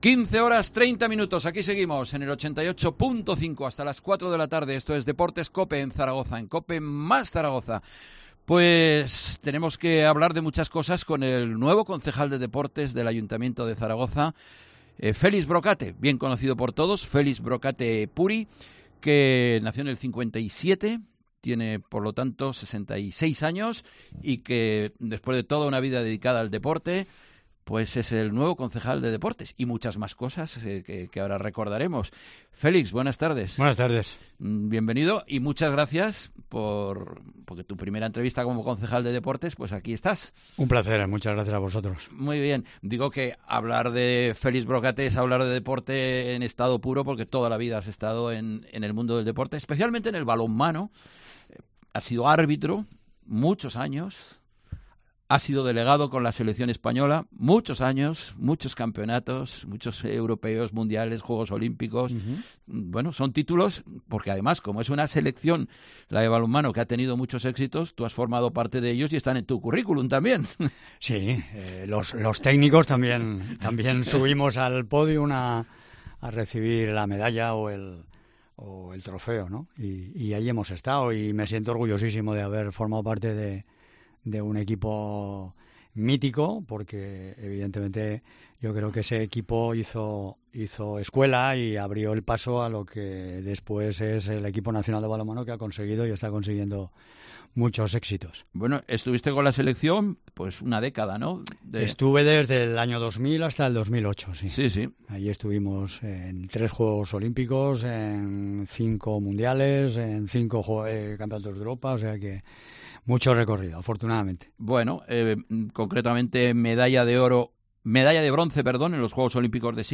Entrevista al nuevo concejal de Deportes del Ayuntamiento de Zaragoza, Félix Brocate.